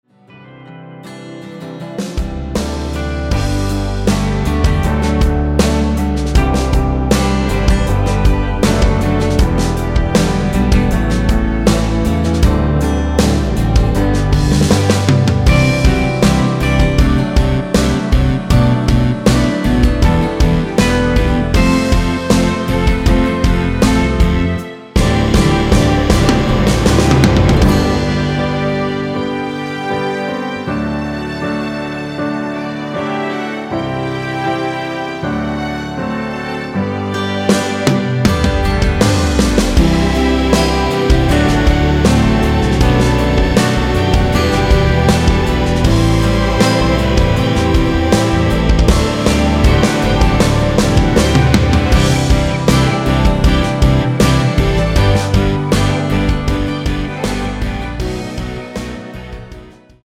-4)편집 MR입니다.
앞부분30초, 뒷부분30초씩 편집해서 올려 드리고 있습니다.
중간에 음이 끈어지고 다시 나오는 이유는